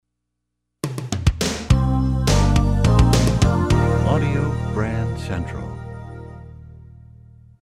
MCM Category: Radio Jingles